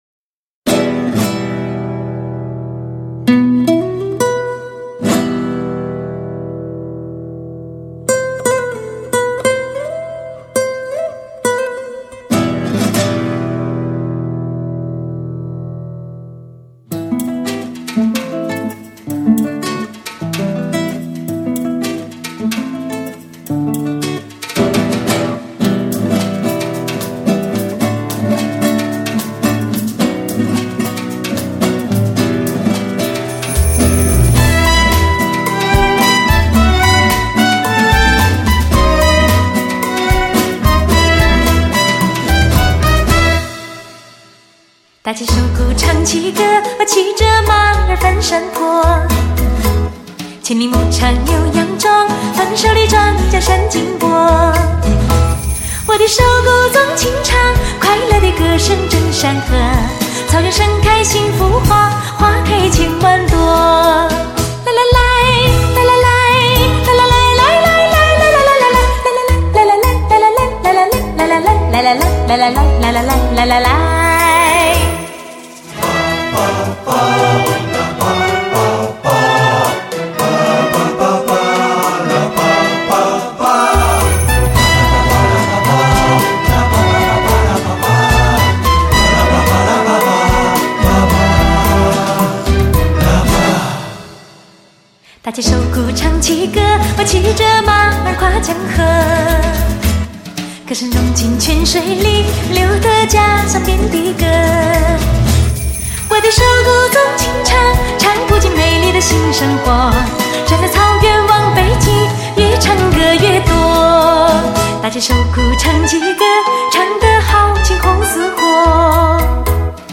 最甜美女声与经典民歌的惊艳邂逅